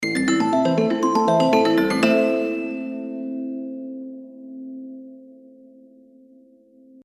There are quite a few fascinating auditory illusions, and one of them creates a "perpetual scale" ladder where the notes continually appear to be going upward or downward ("Shephard Tone") . . .
[NOTE: This is an MP3 (263-kbps [VBR], 612KB, approximately 7 seconds) of the "'Shepard Tone' Ladder Variation (SoMC) ~ End" phrase played by the Concert Pedal Harp (LSO) and Celesta (Miroslav Philharmonik) as shown in the music notation (see above), which among other things provides a clue to one of the musical "secrets" that makes "Dance of the Sugar Plum Fairy" (Tchaikovsky) so fascinating .
Shepard-Tone-Ladder-End.mp3